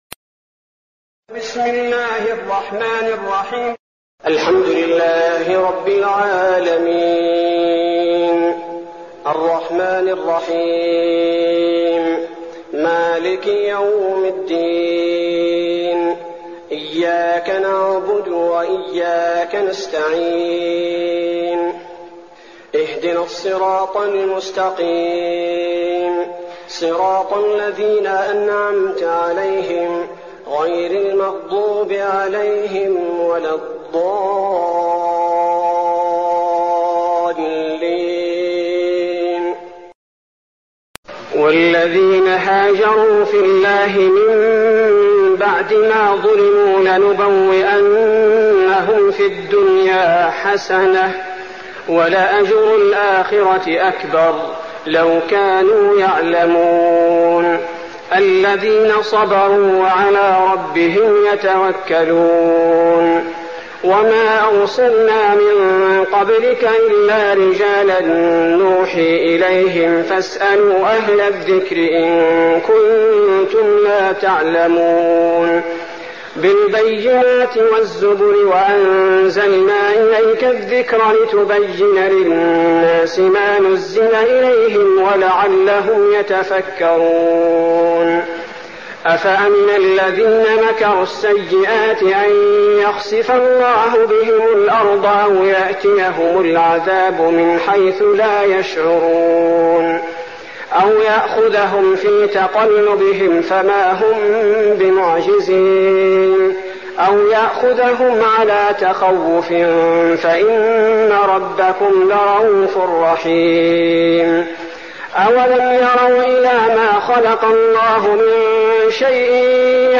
تراويح رمضان 1415هـ سورة النحل (41-128) Taraweeh Ramadan 1415H from Surah An-Nahl > تراويح الحرم النبوي عام 1415 🕌 > التراويح - تلاوات الحرمين